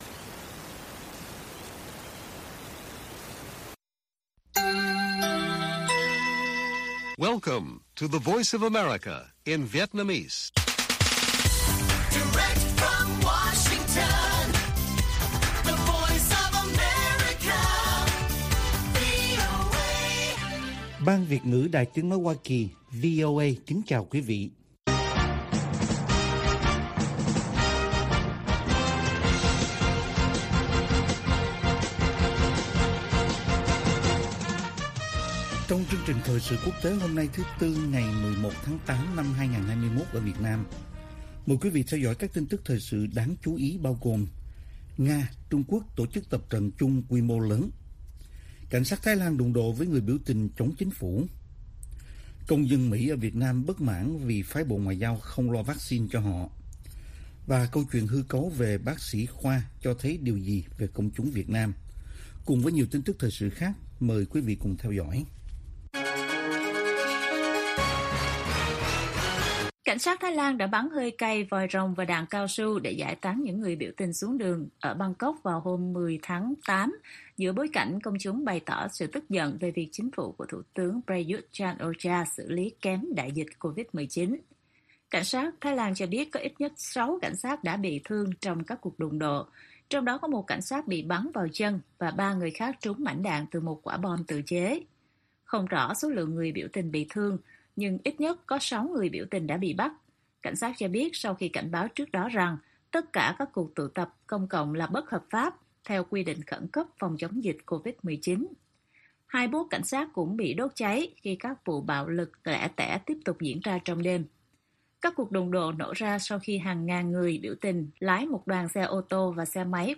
Bản tin VOA ngày 11/8/2021